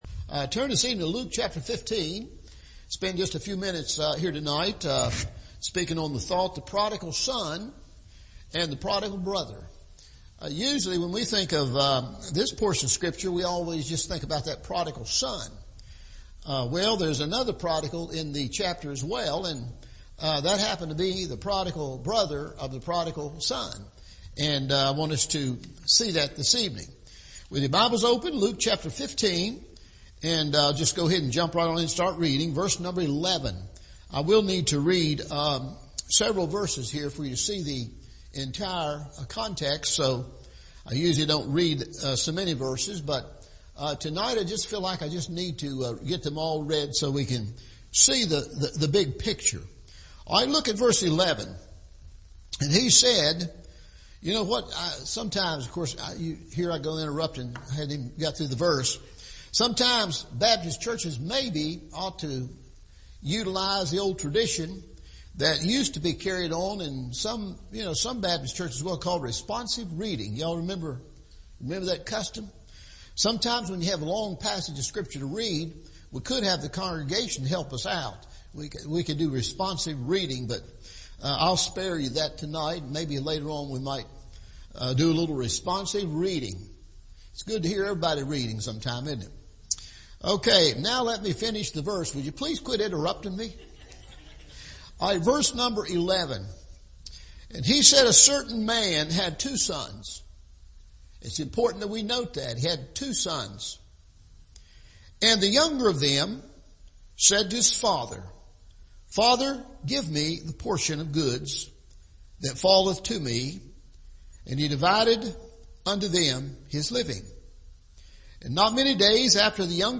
The Prodigal Son and The Prodigal Brother – Evening Service – Smith Grove Baptist Church